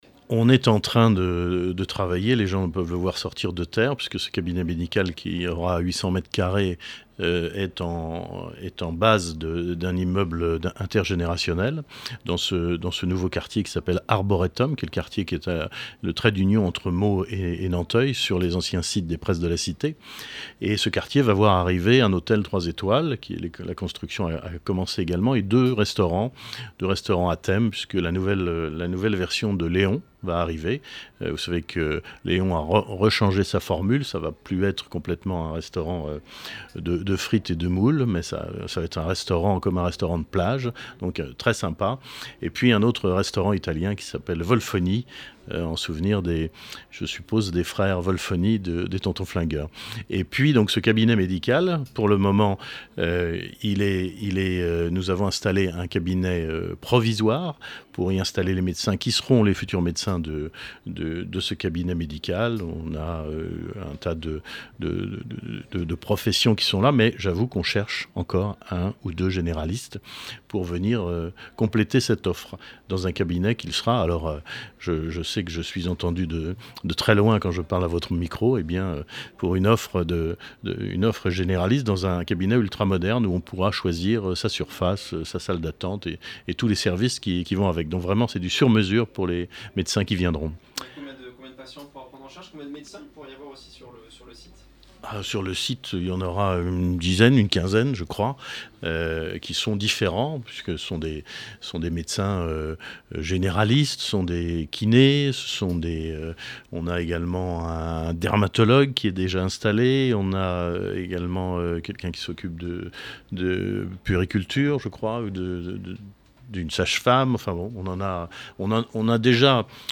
Les collectivités et acteurs locaux du département se sont donnés rendez-vous dans les locaux de Meaux pour une journée spéciale sur Oxygène.